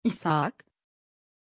Aussprache von Isaak
pronunciation-isaak-tr.mp3